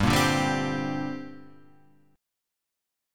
Gmadd11 chord {3 1 0 0 1 3} chord